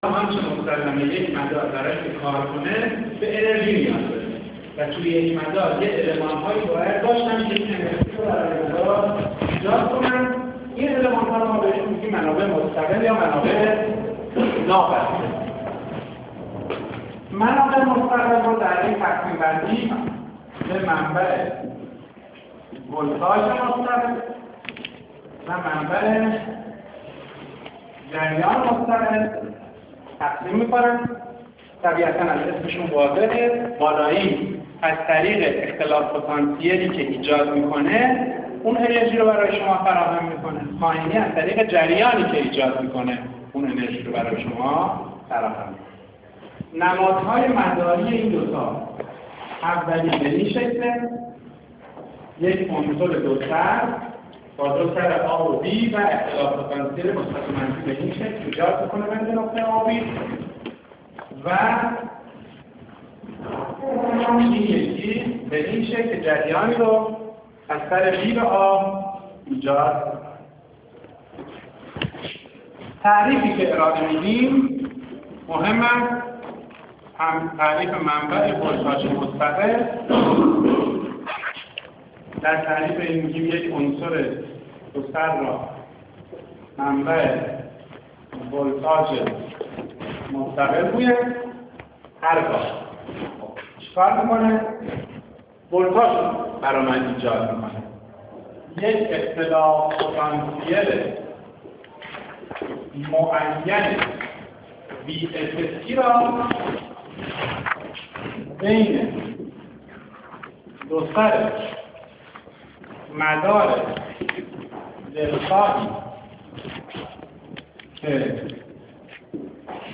تدریس صوتی درس مدارالکتریکی 1